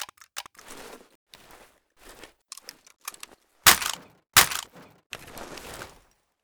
inspect_jammed.ogg